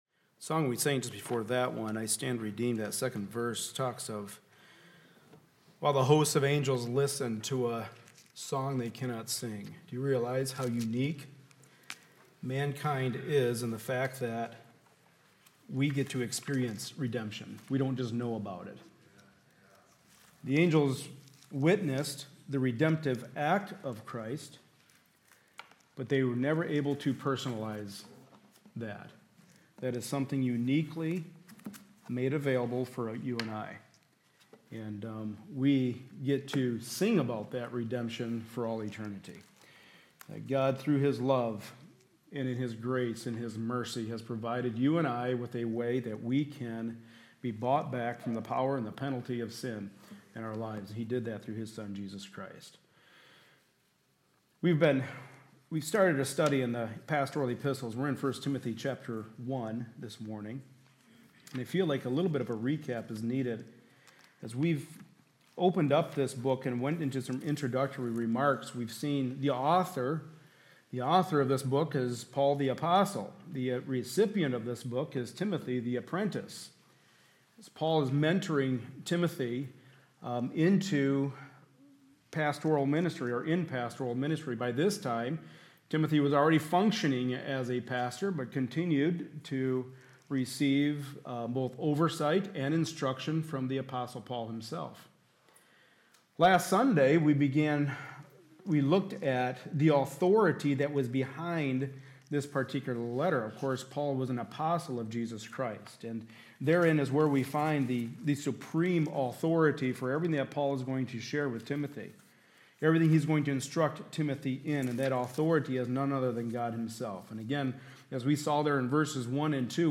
Service Type: Sunday Morning Service A study in the pastoral epistles